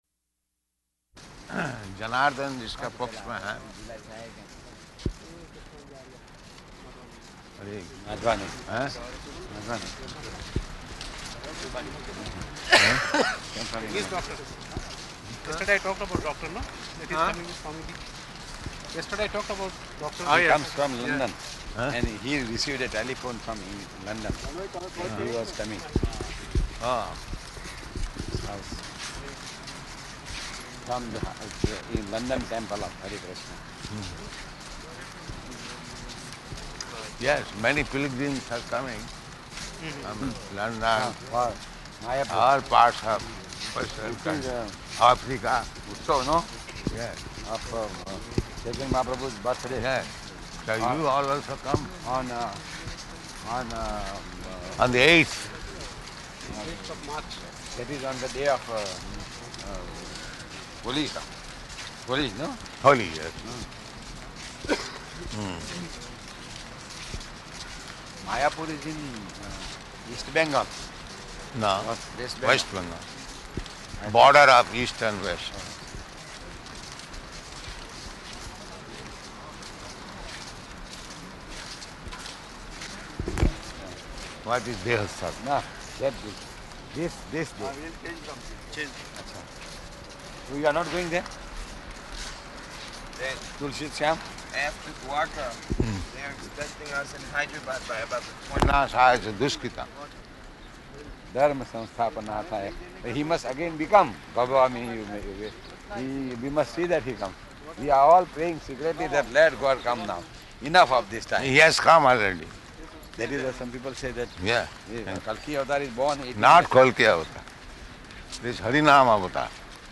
Morning Walk, partially recorded
Type: Walk
Location: Bombay